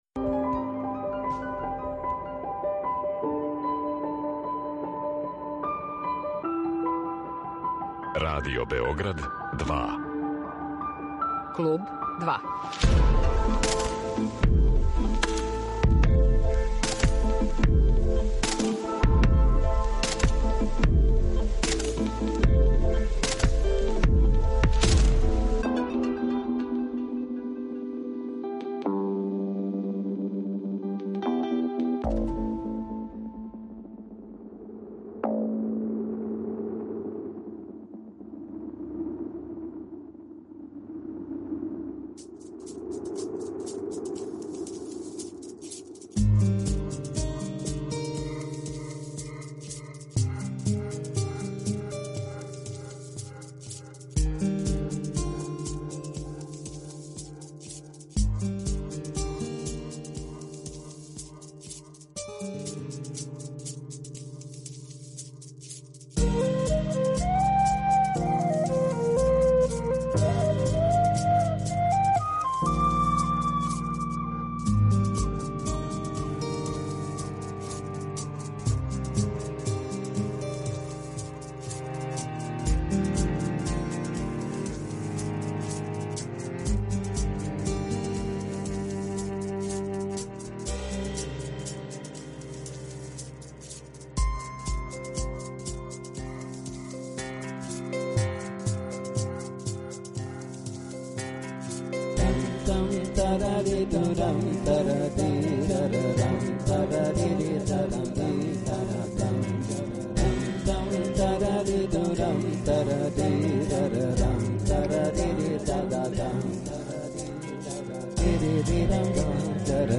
а слушаћемо и његову музику.